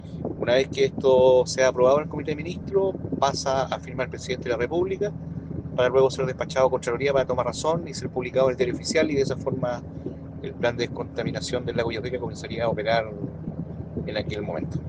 El seremi de Medio Ambiente, Félix Contreras, indicó que posteriormente a que el Consejo de Ministros apruebe el plan, este deberá ser firmado por el Presidente de la República, Gabriel Boric.